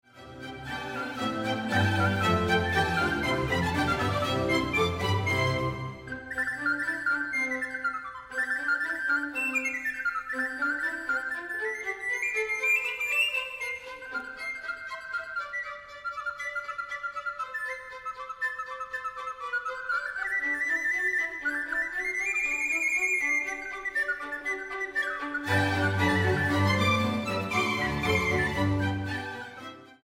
para flauta sopranino, cuerdas y contínuo